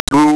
A Go application to generate numbers station like audio output